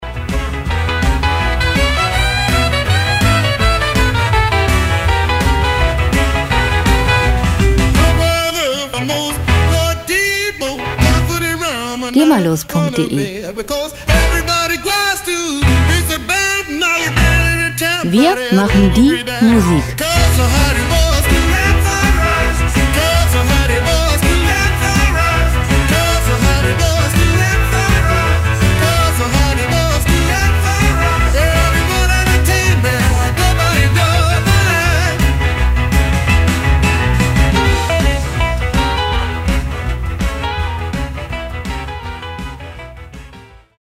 Musikstil: Boogie-Woogie
Tempo: 166 bpm
Tonart: E-Dur
Charakter: mitreißend, wuchtig